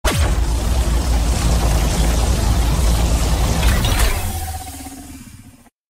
Om Tails Cannon Shot Sound Button - Free Download & Play
Sound Effects Soundboard0 views